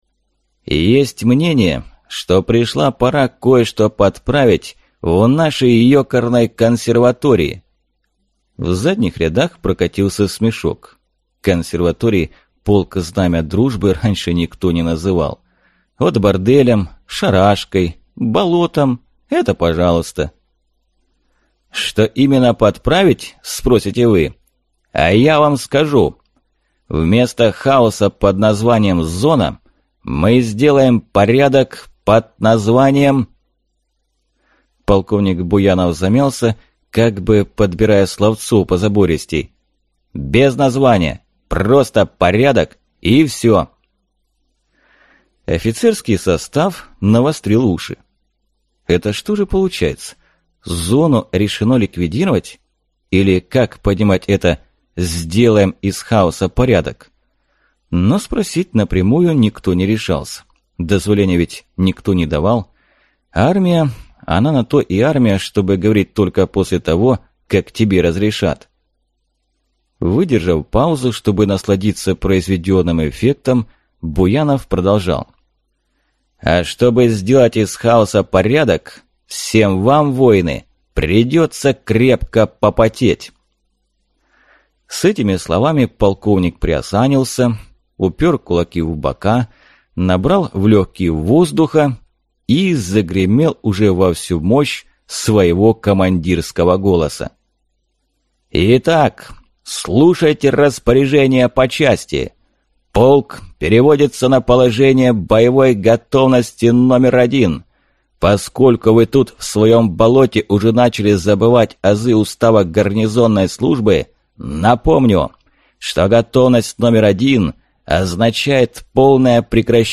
Аудиокнига Полный котелок патронов | Библиотека аудиокниг